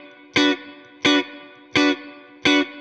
DD_StratChop_85-Bmin.wav